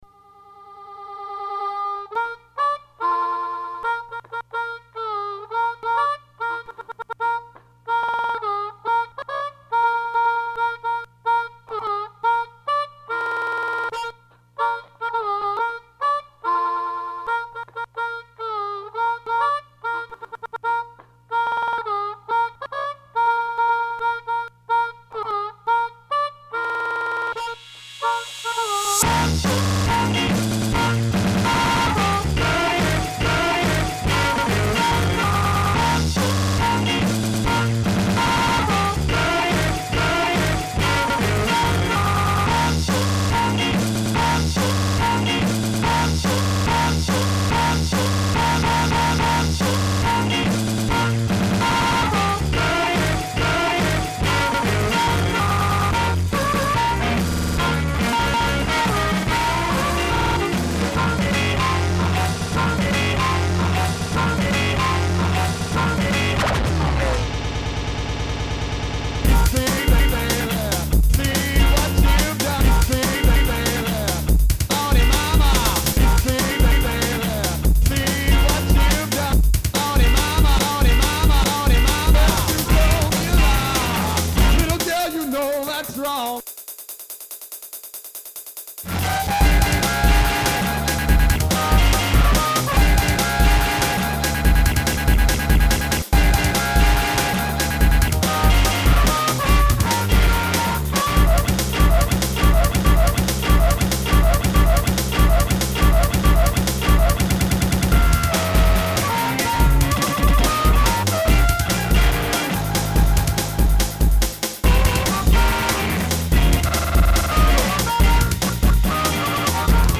a remix